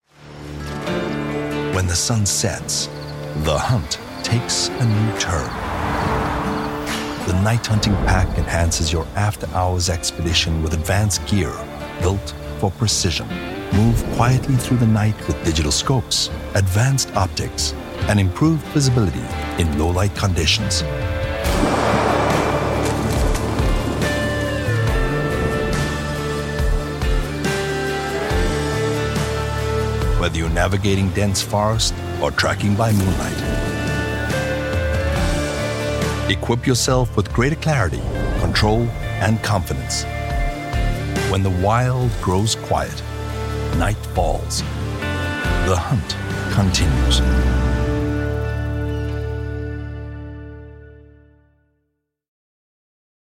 Inglés (sudafricano)
BarítonoBajoContraltoContratenorProfundoBajoMuy bajo